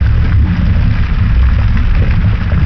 AmbLavaLoop.wav